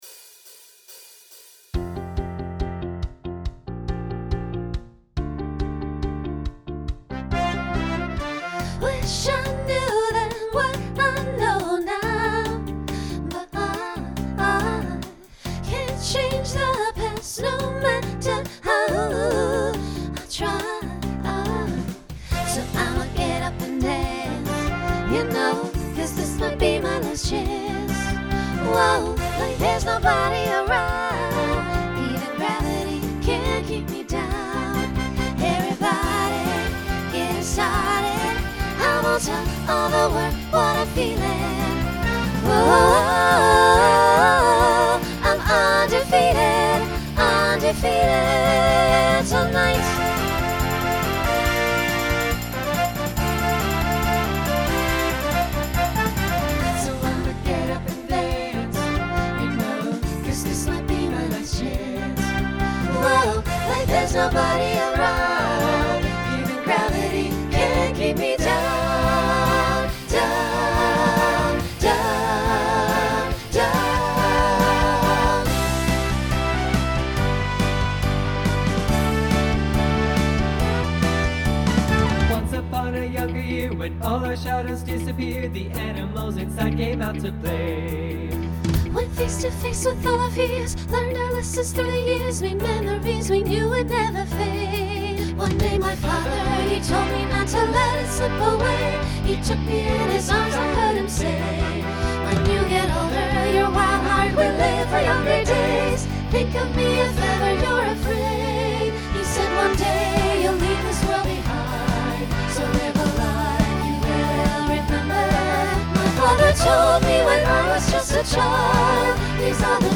SSA trio then SATB
Genre Pop/Dance
Voicing SATB